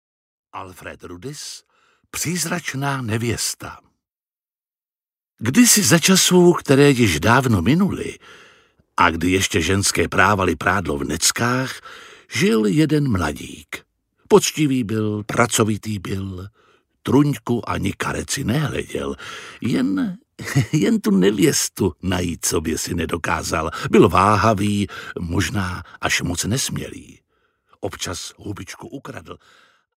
Přízračná nevěsta audiokniha
Mystický příběh mládence, který se oženil se Smrtí v úžasné interpretaci Jiřího Lábuse, držitele Ceny Thálie - činohra a Českého lva za nejlepší herecký výkon.
Ukázka z knihy
• InterpretJiří Lábus
prizracna-nevesta-audiokniha